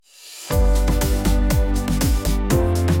rhythmic | energetic